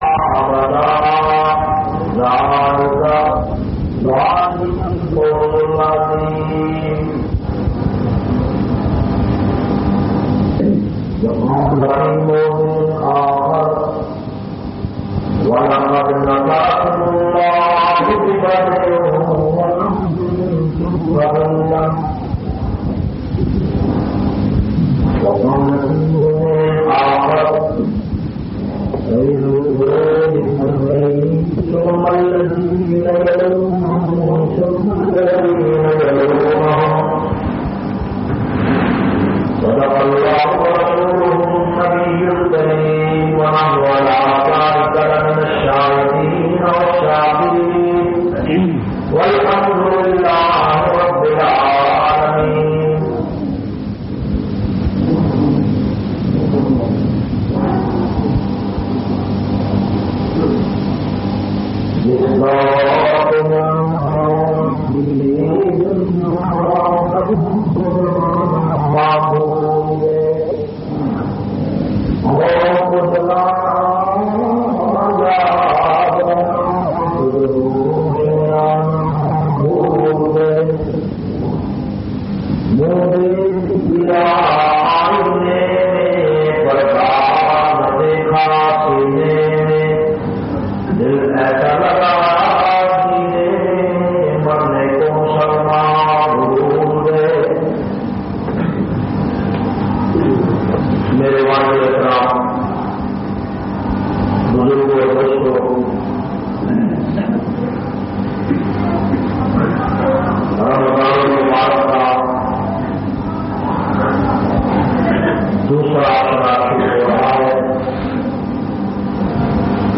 512- Jung e Badr Jumma khutba Jamia Masjid Muhammadia Samandri Faisalabad.mp3